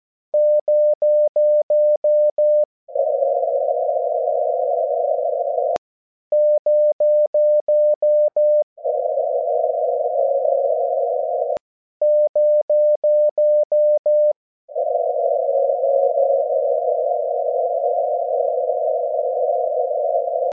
2025-03-04 18:00 UTC - CW echo testing
Did a test of my own CW echoes, even with my modest station, it is perfectly possible to work stations in CW mode, below is a short echo test of my own signal.